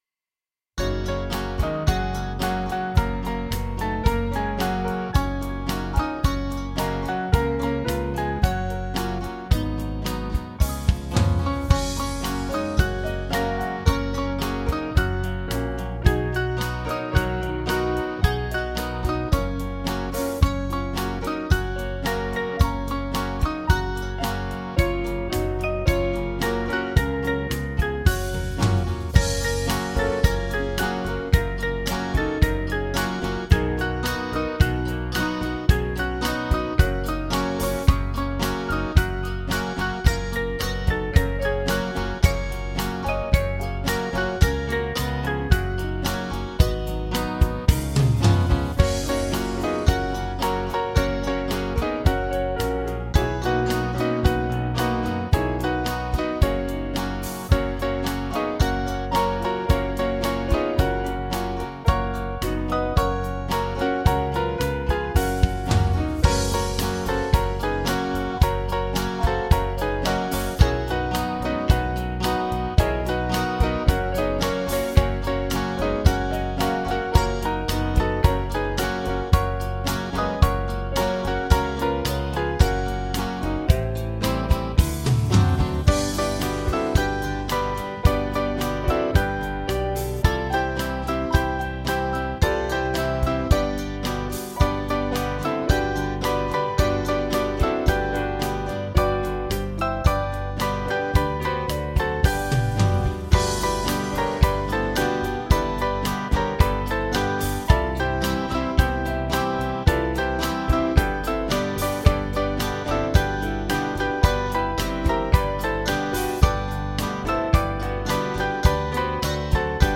Small Band
(CM)   3/Gb-G
Country Feel   470.8kb